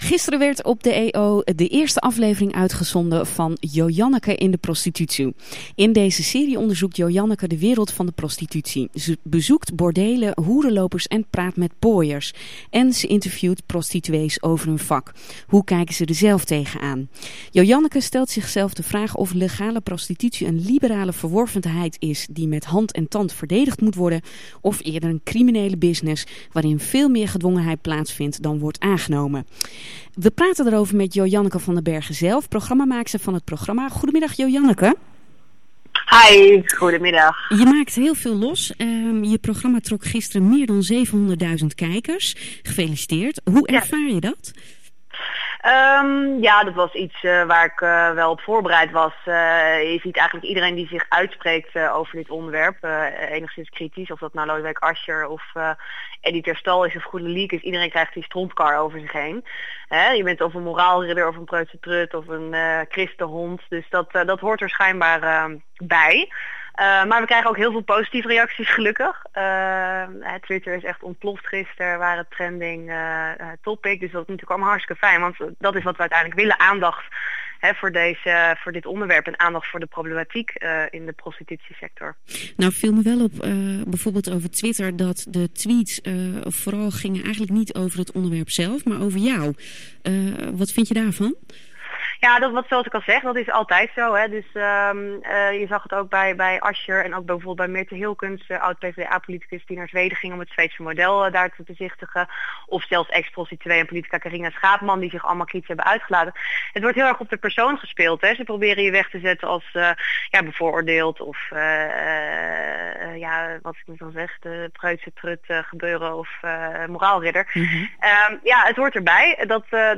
Een stortvloed aan positieve en negatieve kritieken kreeg ze over zich heen. Het Ritme van de Stad belde haar om te horen hoe zij dat ervaart. Luister naar het interview met Jojanneke.